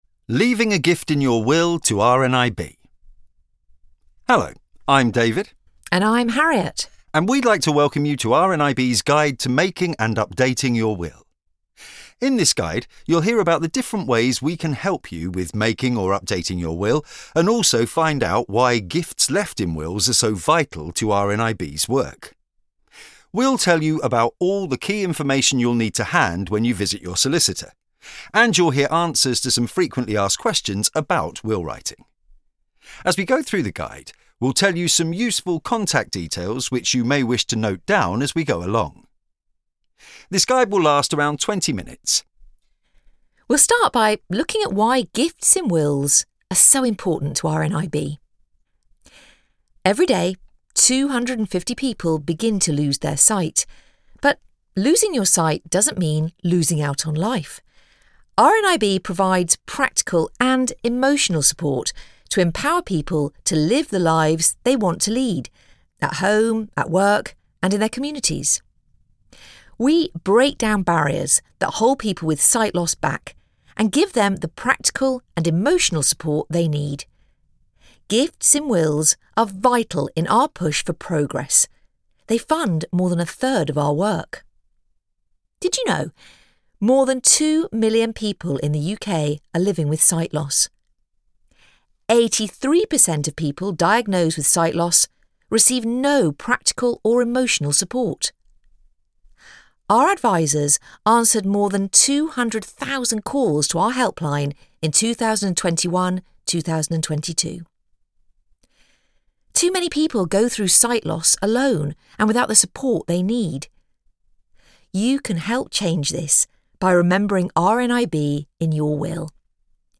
If you'd like to find out more about why it's important to have an up to date Will, hear the stories of people who have been helped by RNIB's services and understand the difference you can make by giving in this way, then this narrated recording of our guide 'Leaving a gift in your Will to RNIB' is for you!